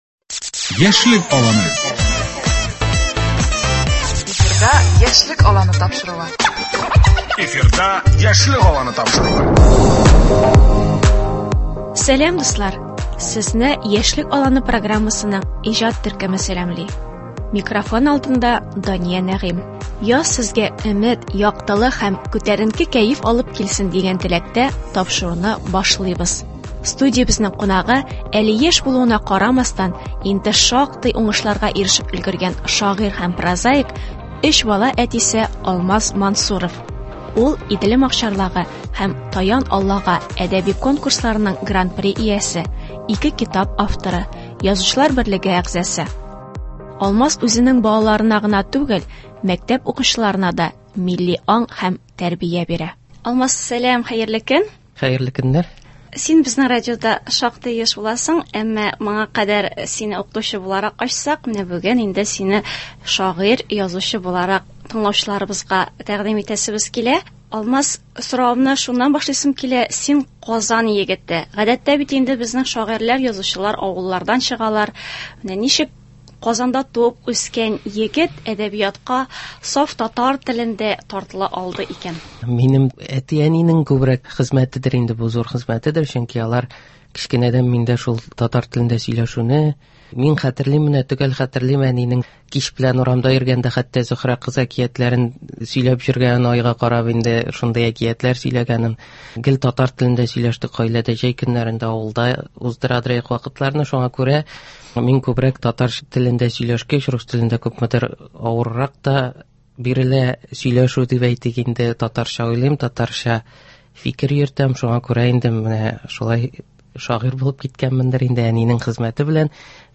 Студиябезнең кунагы